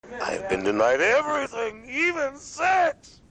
A special quote of Keith David, voice of Goliath/Thailog, as he reveals the truth about his past.